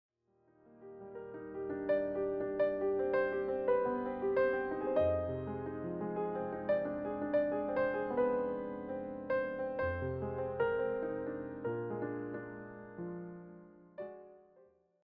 warm and familiar piano arrangements
solo piano